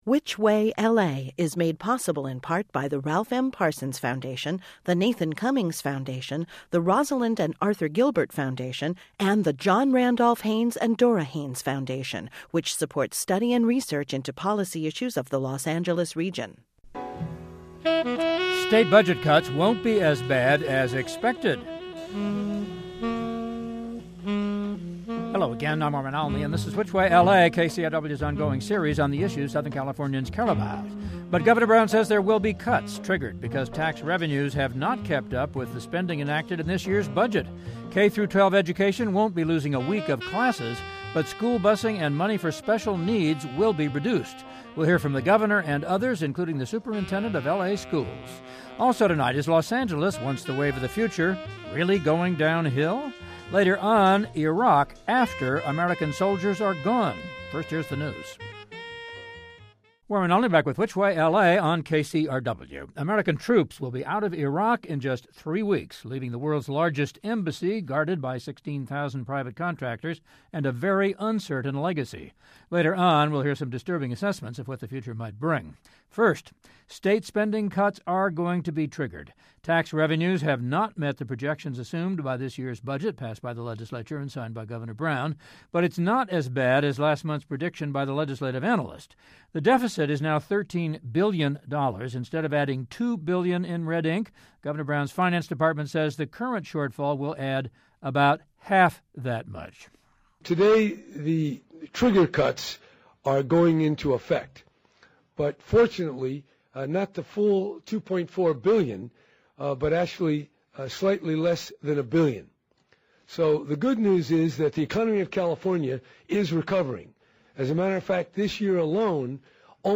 K-12 education won't be losing a week of classes, but school busing and money for special needs will be reduced. We hear from the Governor's Office, superintendent of LA Schools and other.